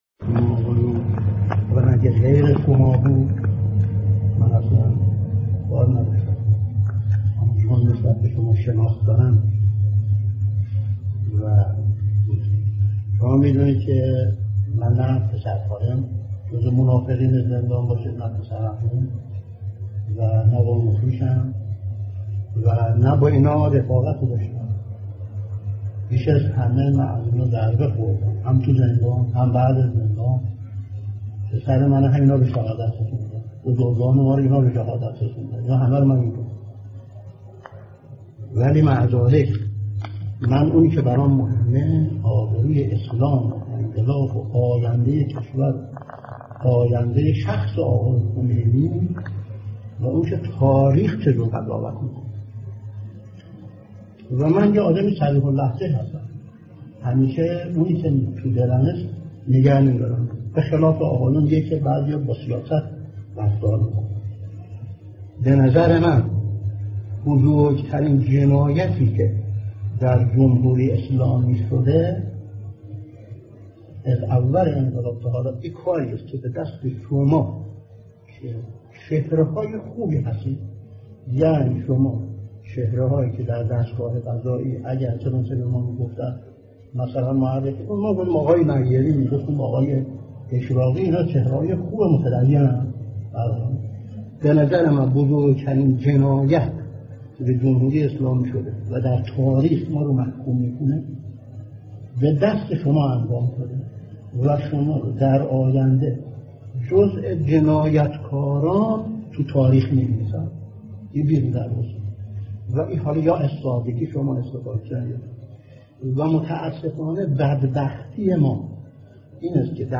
فایل سخنان اعتراضی حسینعلی منتظری به اعدام های سال ۶۷